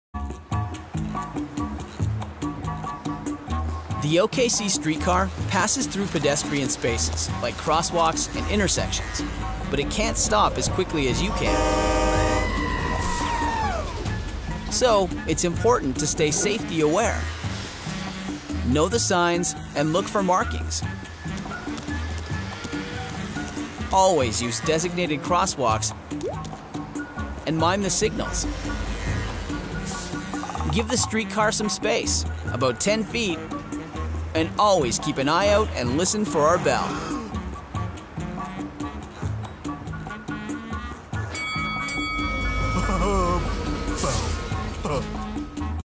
Native Voice Samples
E-learning
-Professional studio with sound booth
English (Canadian)